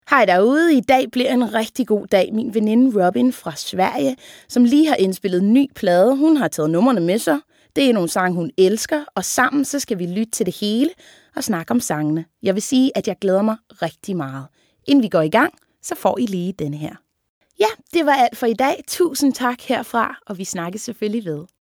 » Stemmeprøver